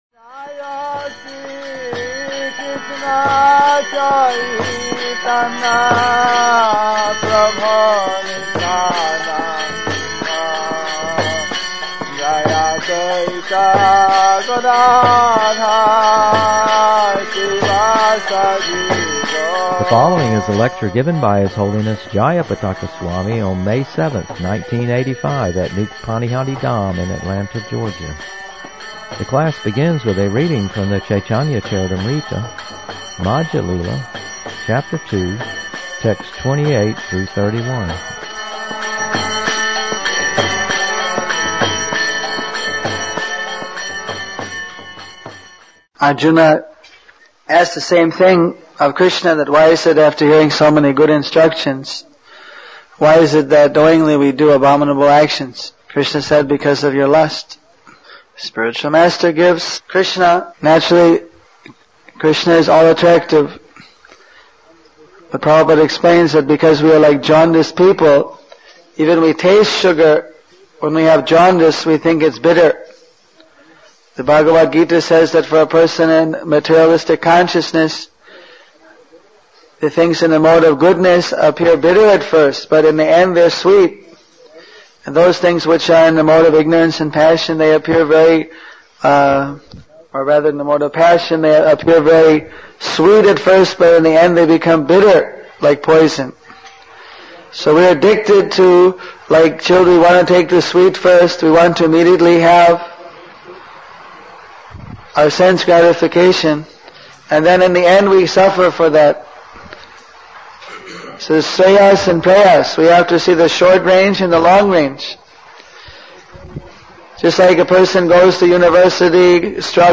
Lectures
The class begins with a reading from the Caitanya Caritamrita Madhya lila Chapter 2, Text 28-31.